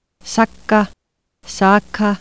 7.7: islandsk [ˈsak:a ˈsa:ka]